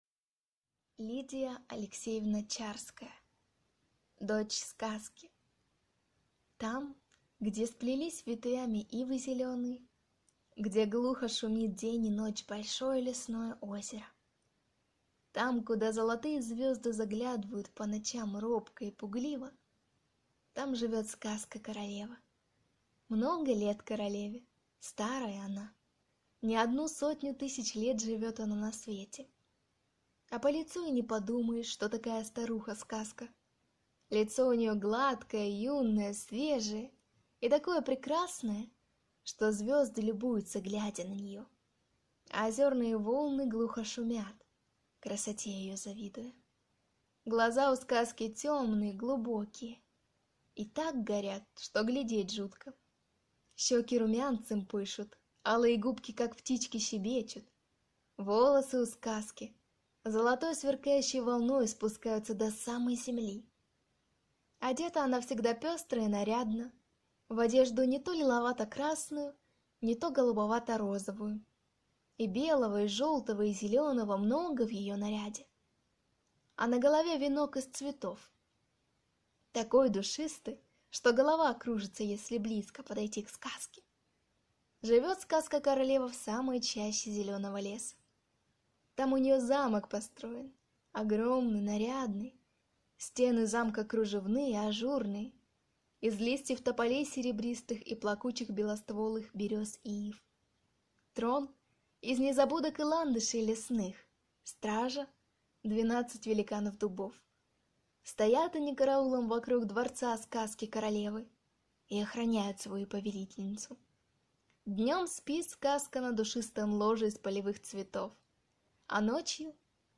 Аудиокнига Дочь Сказки